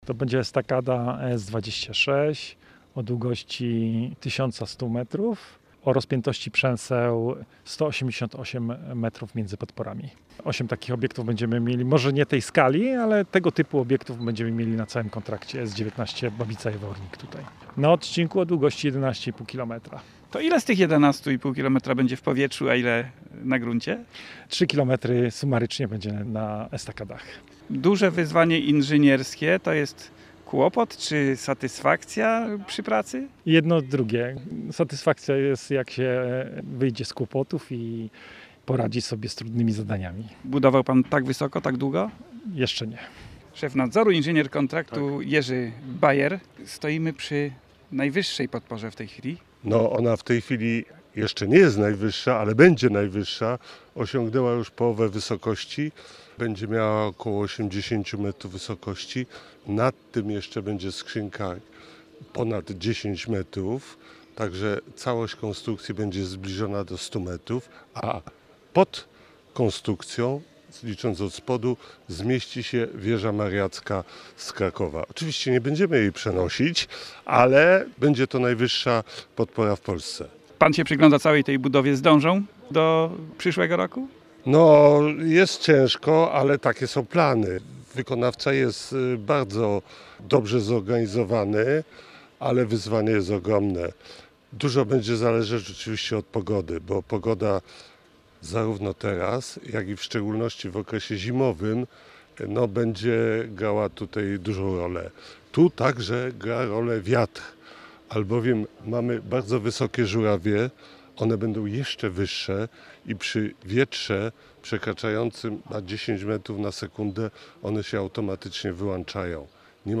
rozmawiał z wykonawcami przy najdłuższej estakadzie